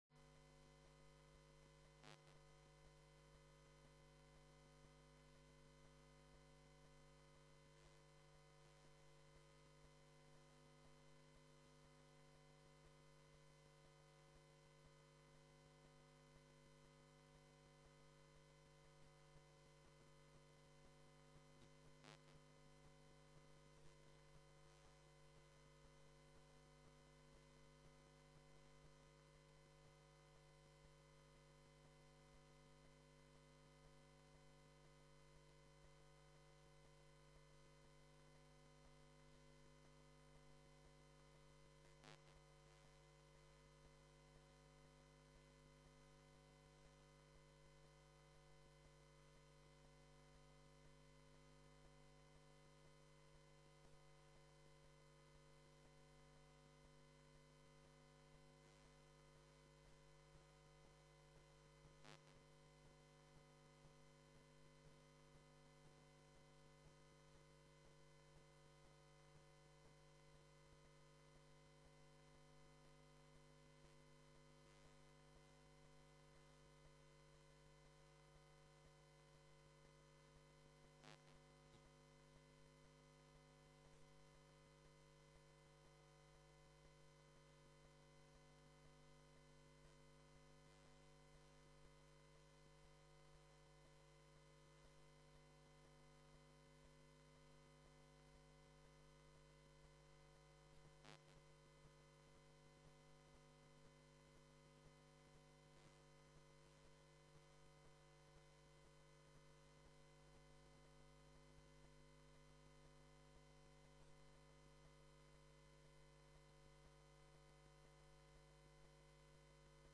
Gemeenteraad 03 maart 2015 20:00:00, Gemeente Goirle
Locatie: Raadzaal